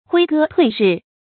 挥戈退日 huī gē tuì rì
挥戈退日发音
成语注音ㄏㄨㄟ ㄍㄜ ㄊㄨㄟˋ ㄖㄧˋ